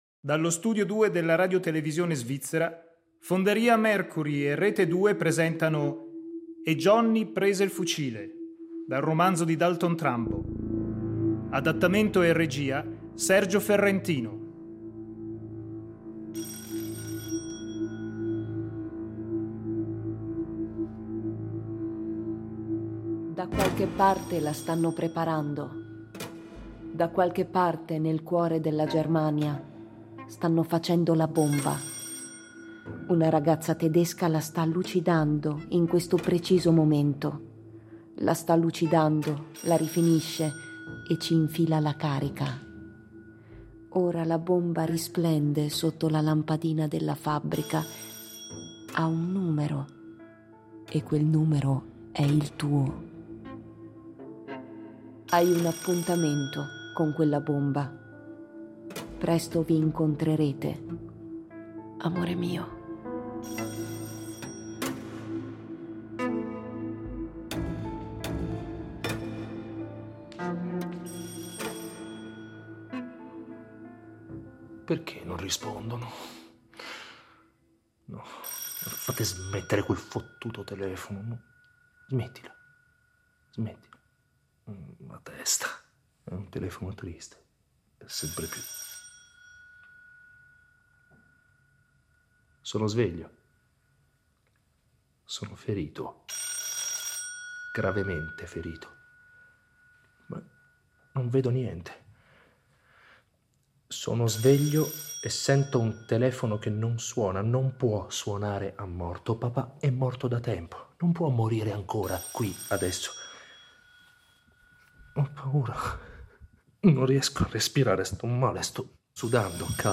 Dal punto di vista teatrale si può intuire un dolorosissimo monologo… ma dal punto di vista radiofonico entrano nel campo acustico i suoni, gli effetti e i tre livelli di recitazione su cui è impostato tutto l’adattamento: pensare, sognare, ricordare. Tre impostazioni diverse per narrare, intrecciate tra loro ma con caratteristiche acustiche saldamente separate dalla storia e dall’uso della voce.